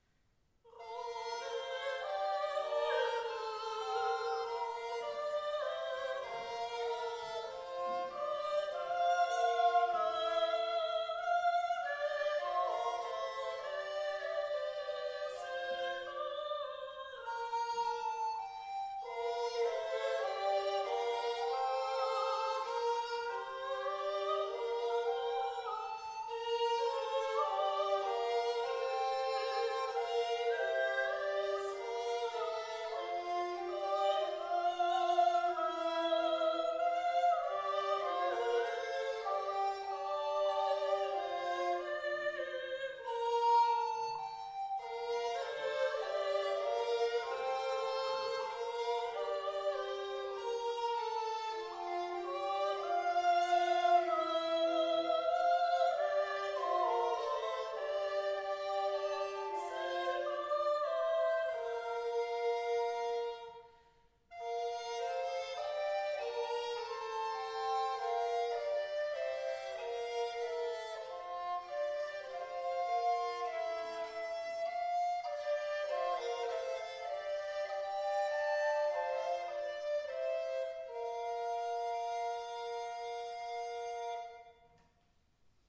Die Hörbeispiele sind Live-Mitschnitte aus unterschiedlichen
Gesang, Altblockflöte, Tenorblockflöte, Fidel